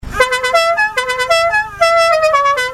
Fanfare Soundfile
MeinCTR-Fanfare.mp3